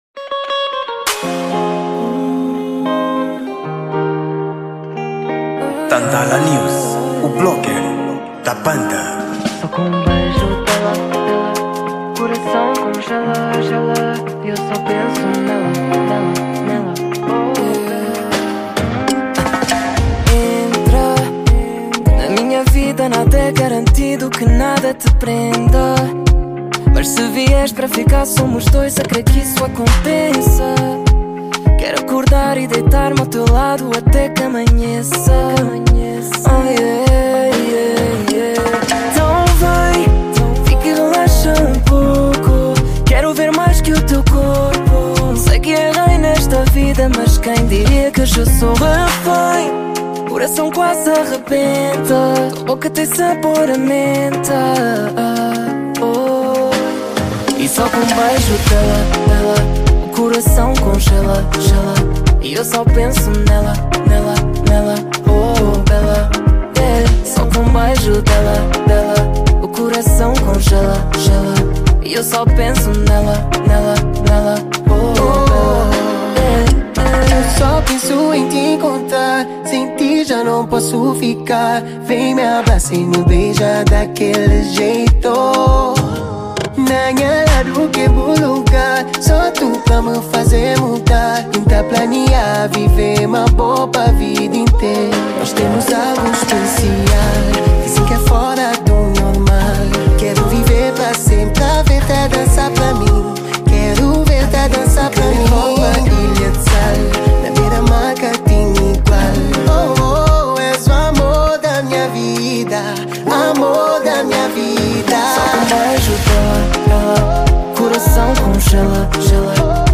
Gênero: Dance Hall